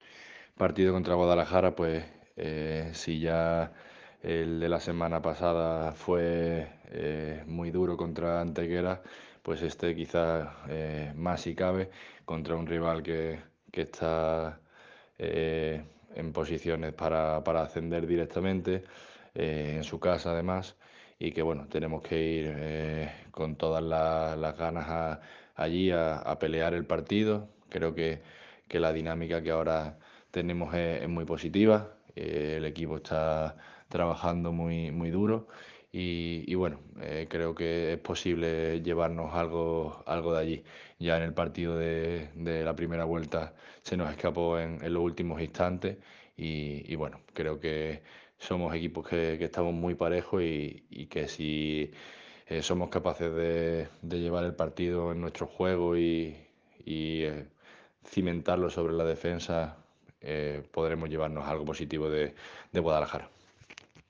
Manifestaciones del técnico rival